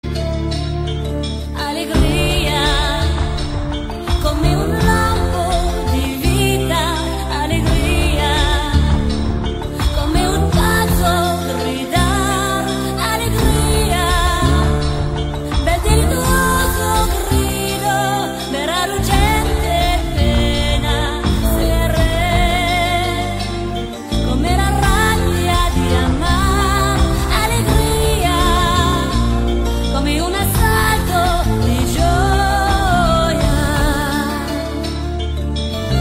Estas pezas musicais tratan de evocar alegría.
• Tempo rápido.
• Melodías agudas.
• Ritmo marcado.